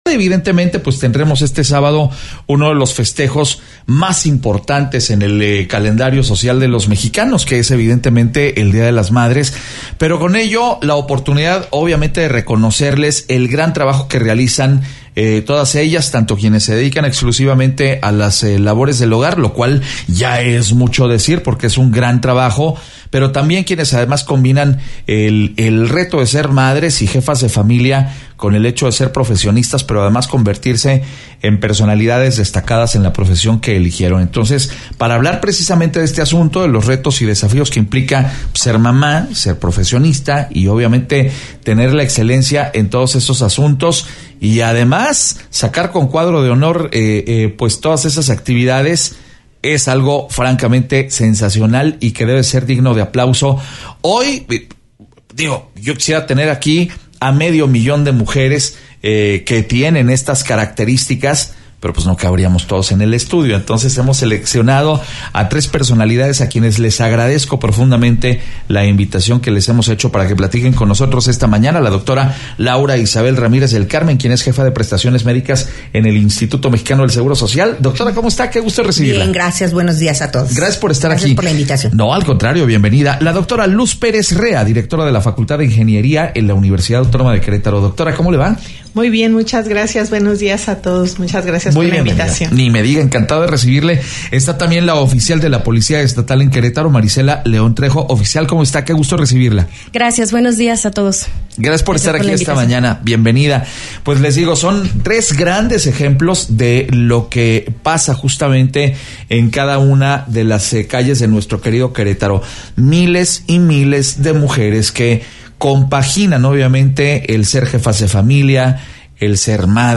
Mesa de Análisis